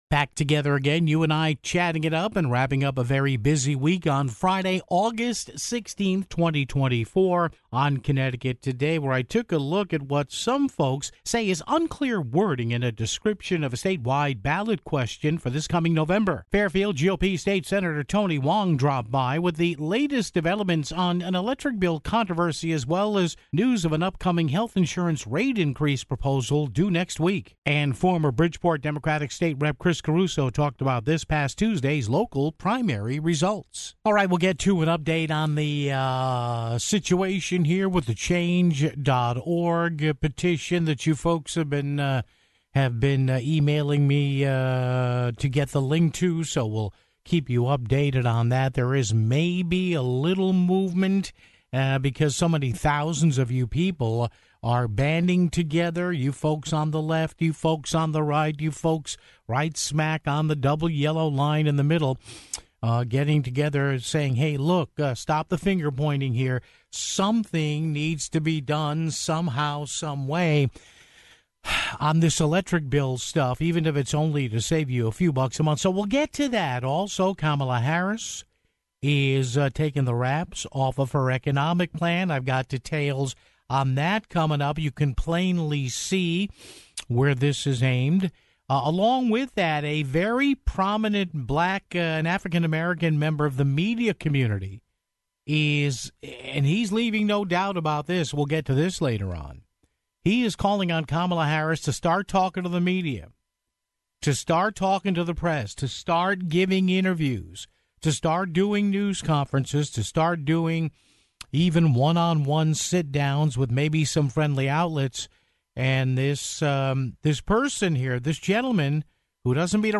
Fairfield GOP State Sen. Tony Hwang dropped by with the latest developments on an electric bill controversy, as well an upcoming health insurance rate increase proposal due next week (14:06). Former Bridgeport State Rep. Chris Caruso talked about some of this past Tuesday's local primary results (25:12) Image Credit